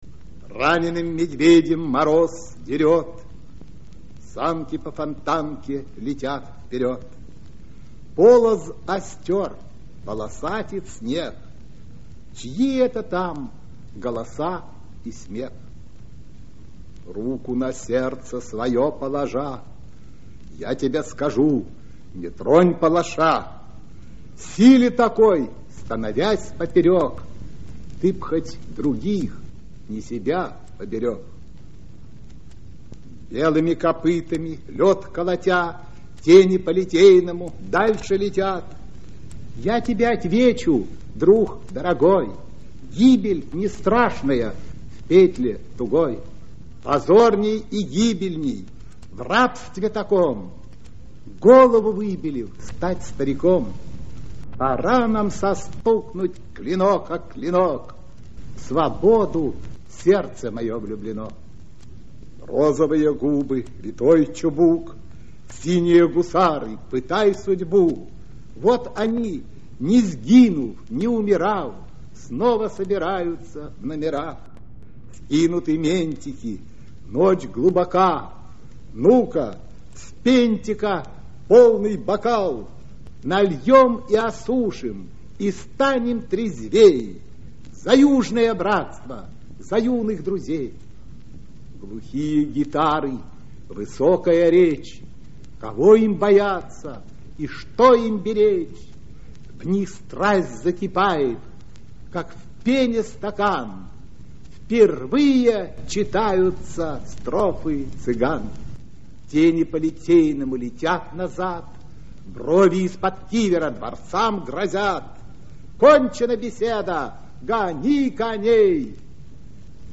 3. «Николай Асеев – Синие гусары (читает автор)» /